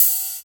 103 OP HAT.wav